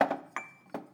Babushka / audio / sfx / Kitchen / SFX_Glass_03.wav
SFX_Glass_03.wav